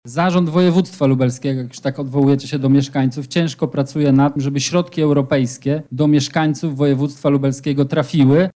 Zakończyła się sesja Sejmiku Województwa Lubelskiego.
Samorząd województwa lubelskiego nie ma wpływu na prowadzone negocjacje przez polski rząd– argumentował wicemarszałek województwa lubelskiego Michał Mulawa: